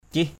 /cih/